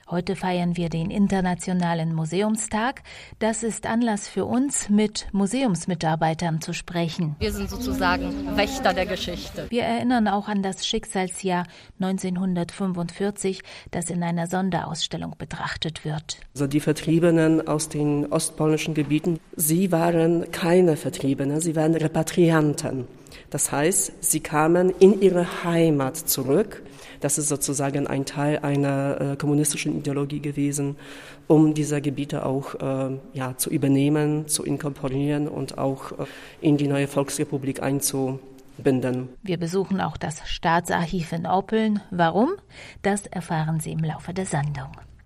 Heute feiern wir den internationalen Museumstag, das ist Anlass für uns, mit Museumsmitarbeitern zu sprechen.